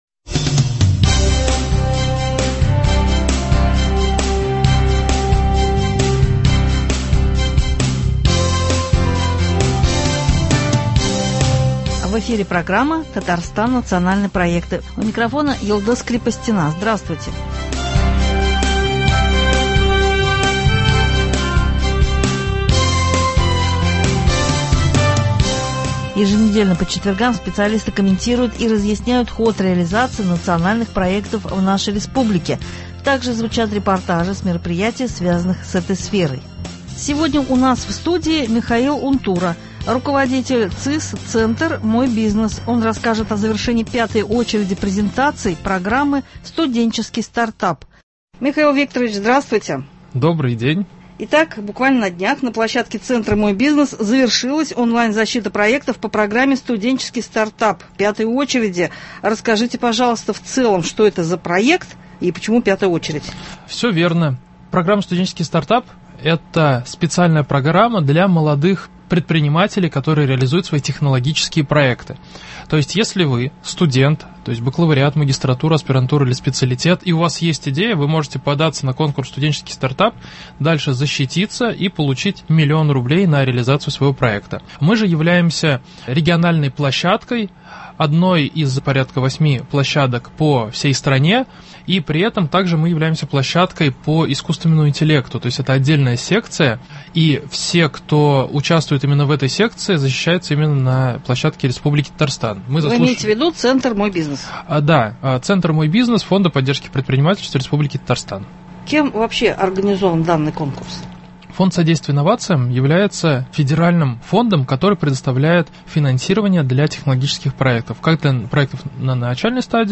Еженедельно по четвергам специалисты комментируют и разъясняют ход реализации Национальных проектов в нашей республике. Также звучат репортажи с мероприятий, связанных с этой сферой.
-Далее министр труда, занятости и социальной защиты Татарстана Эльмира Зарипова расскажет о мероприятиях Года Семьи в рамках реализации Национального проекта «Демография».